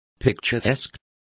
Also find out how charro is pronounced correctly.